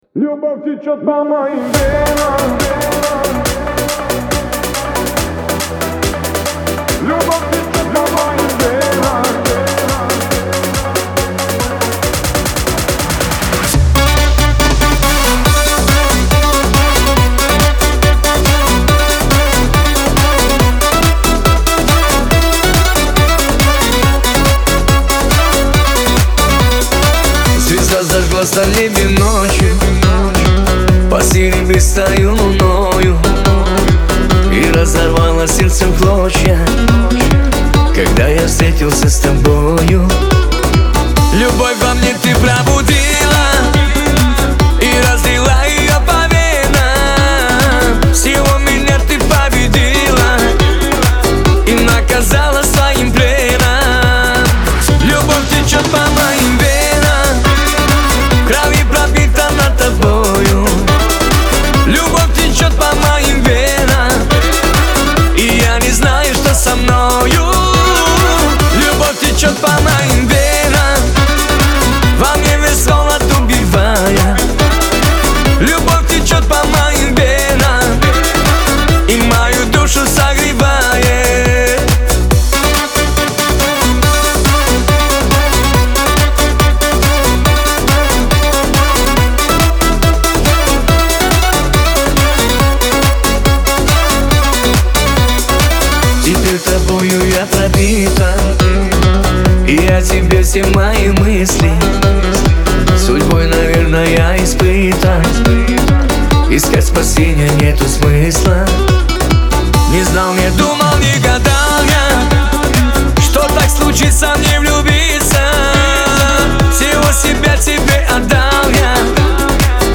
Главная ➣ Жанры ➣ Кавказ поп. 2025.